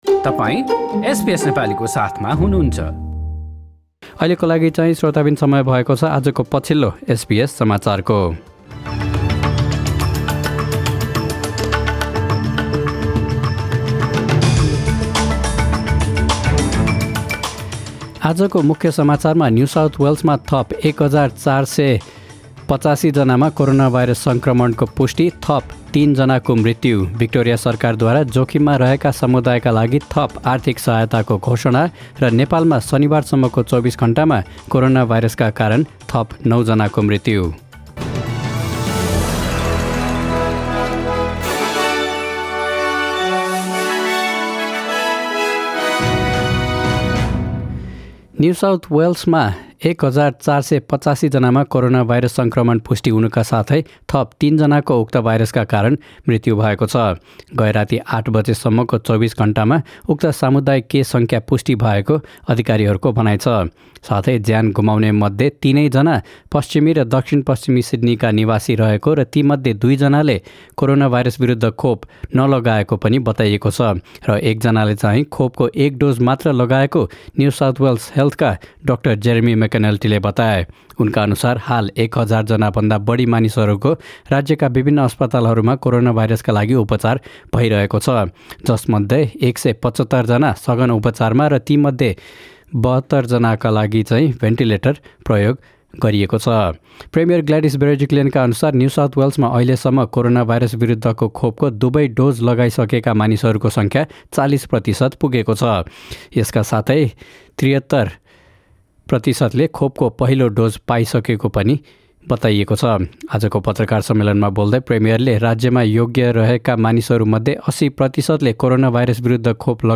Listen to the latest news headlines from Australia in Nepali.